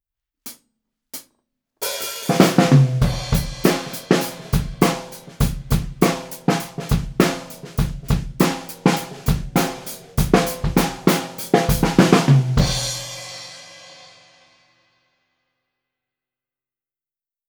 すべて、EQはしていません。
④　ドラマー目線
最後にドラマーの後ろからマイキングしました。
ドラマーが座って頭の高さぐらいに設置しました。
一番、丸いような印象の音質になりました。